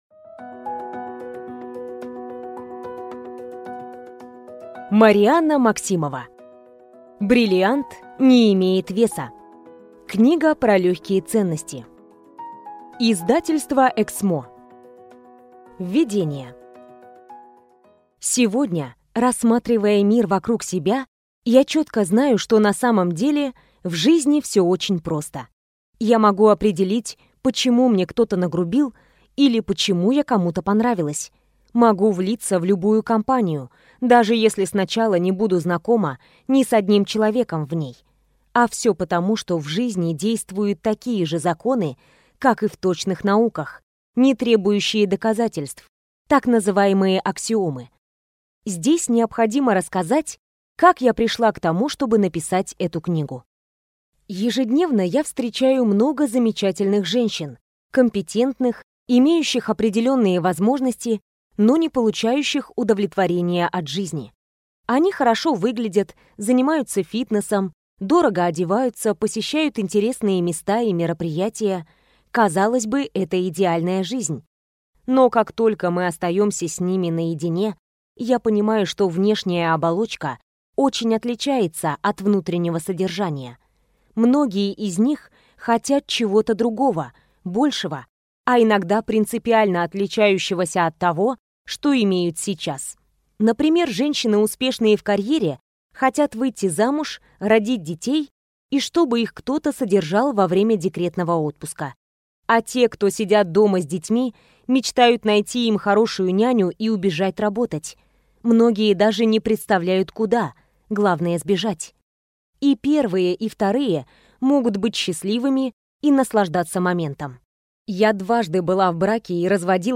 Аудиокнига Бриллиант не имеет веса. Книга про легкие ценности | Библиотека аудиокниг